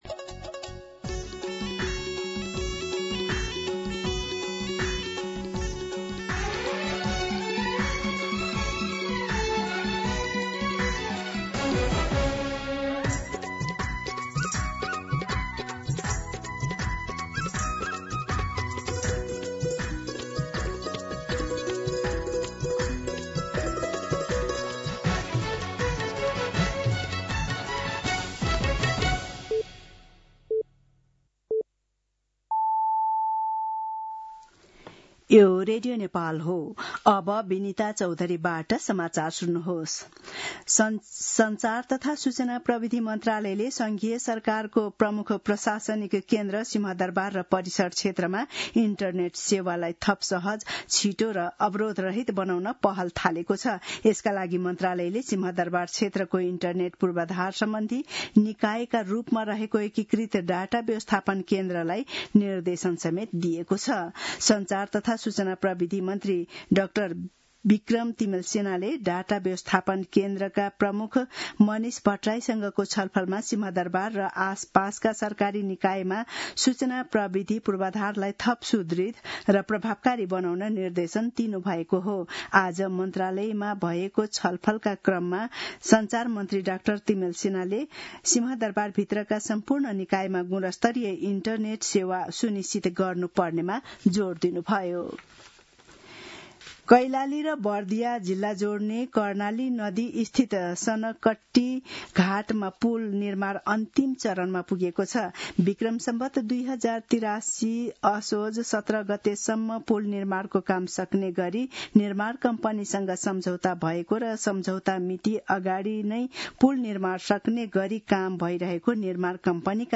An online outlet of Nepal's national radio broadcaster
दिउँसो १ बजेको नेपाली समाचार : ३० चैत , २०८२
1pm-News-30.mp3